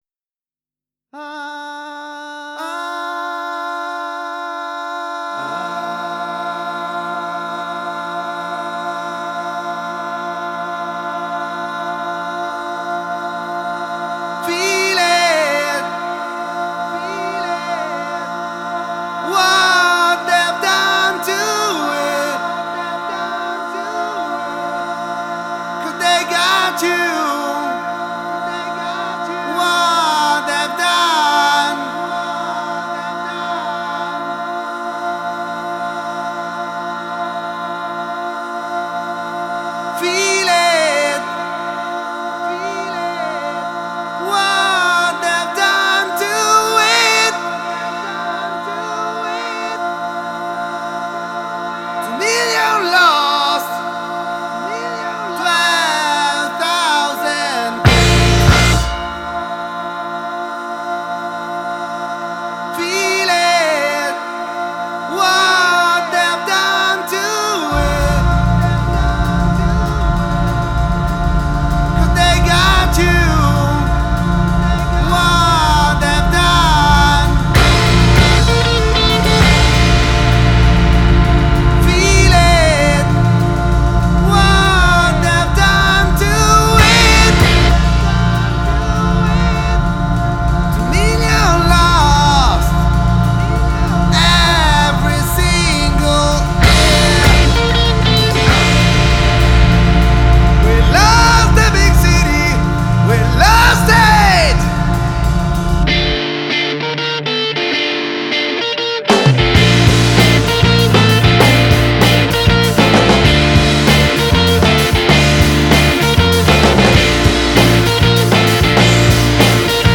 post punk tendu et spontané